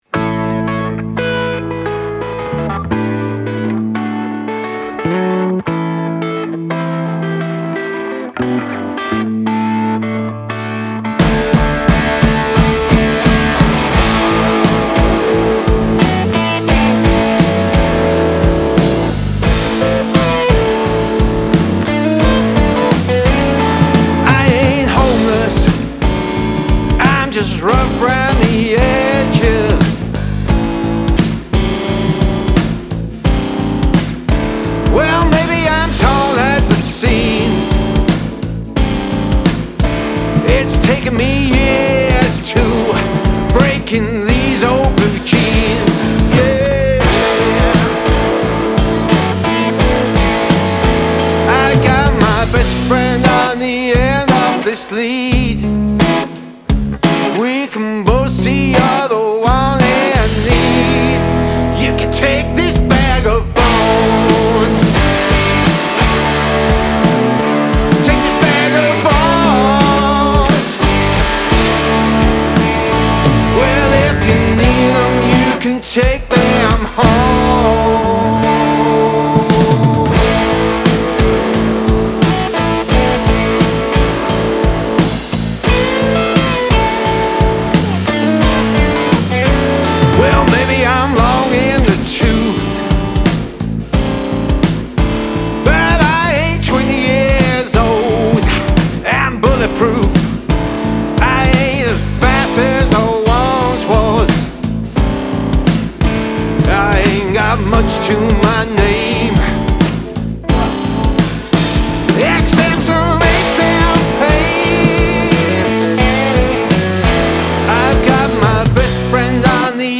Male Vocal, Guitar, Bass Guitar, Drums